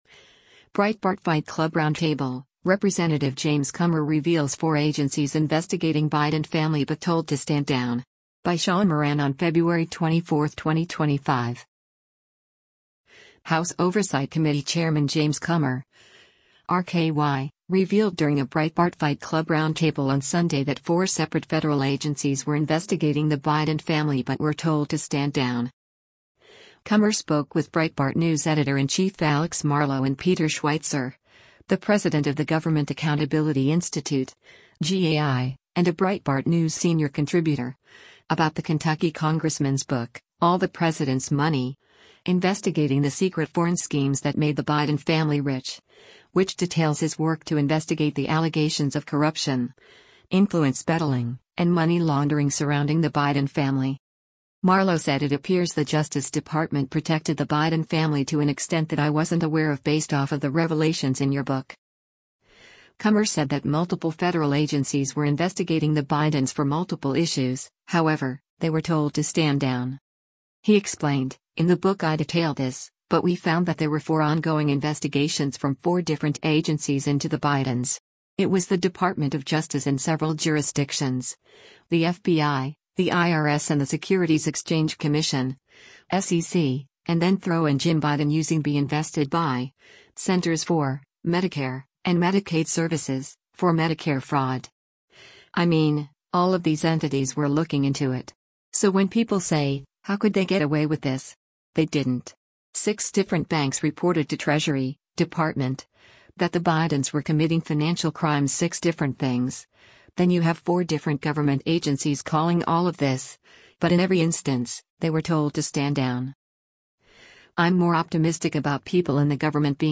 House Oversight Committee Chairman James Comer (R-KY) revealed during a Breitbart Fight Club Roundtable on Sunday that four separate federal agencies were investigating the Biden family but were told to stand down.